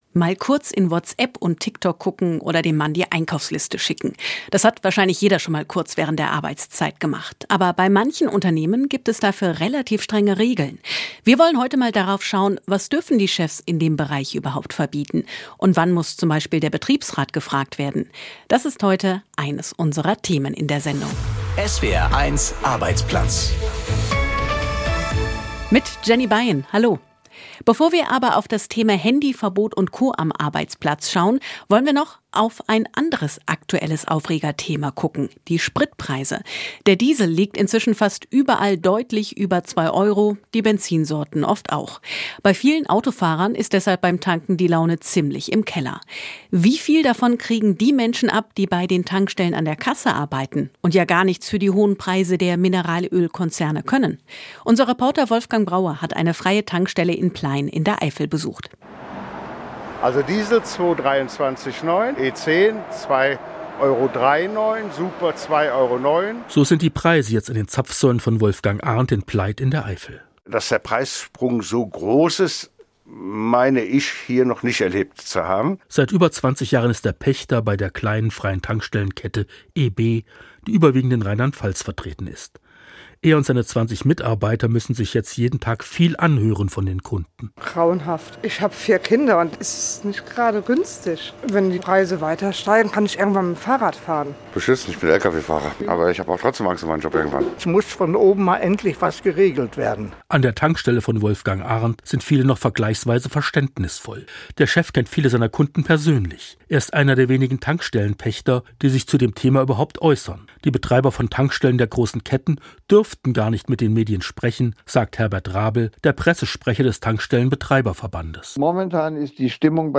Wir fragen die Menschen in Mainz, was sie von solchen Verboten halten ++ Handynutzung nur in der Pause oder sogar gar nicht - was dürfen Vorgesetzte bestimmen?